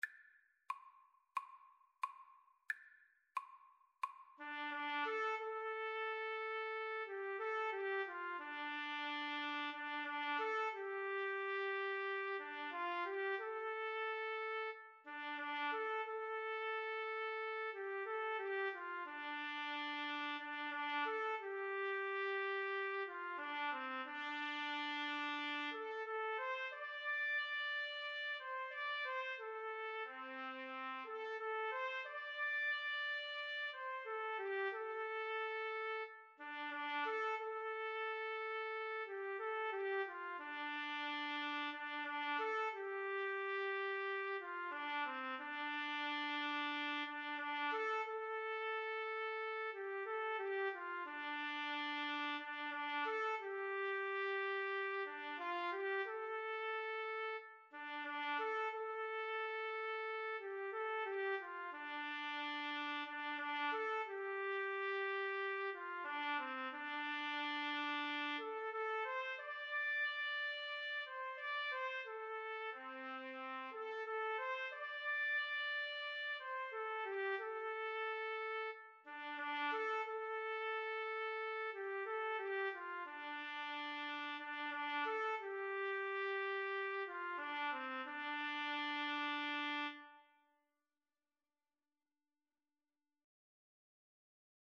4/4 (View more 4/4 Music)
Andante = c. 90